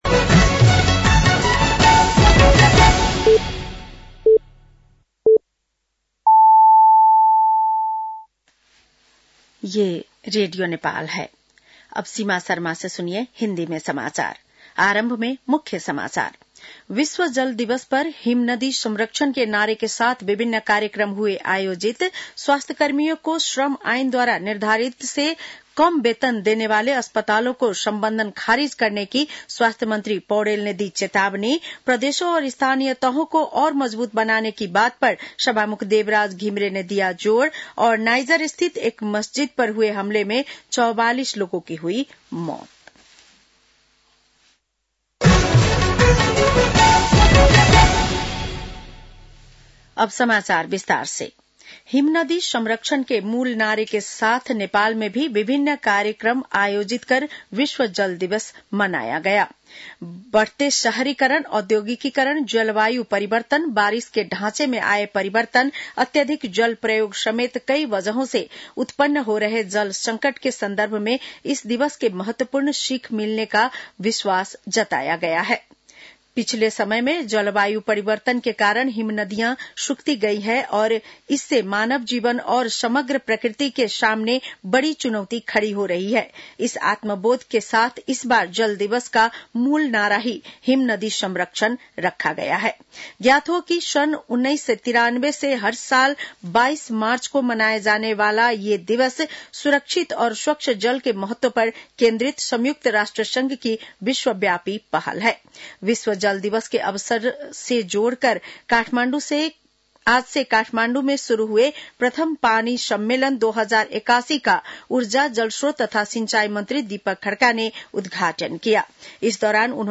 बेलुकी १० बजेको हिन्दी समाचार : ९ चैत , २०८१